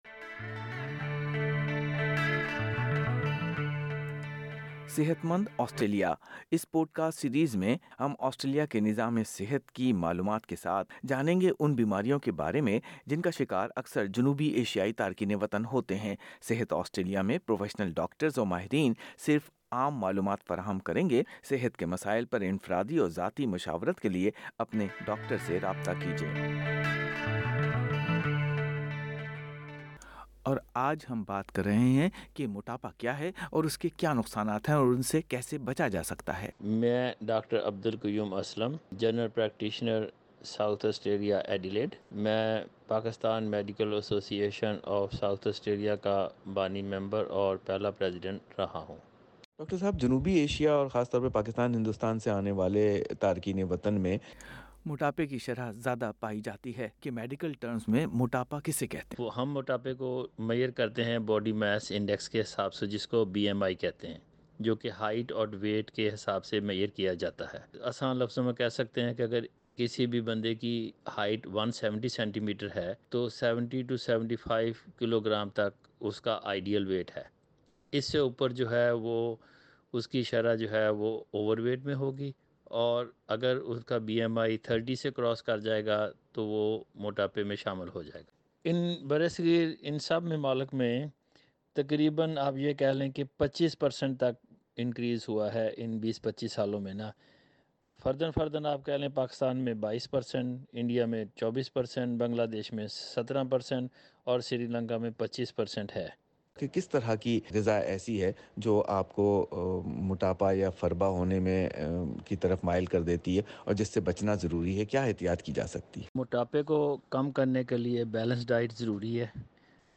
اگر آپ موٹاپے کی وجہ سے فکر مند ہیں، اس سے جڑے صحت کے مسائل کا سامنا ہے، یا بڑھتے وزن کے بارے میں رہنمائی کی ضرورت ہے تو یہ بات چیت آپ کے لئے اہم ہو سکتی ہے۔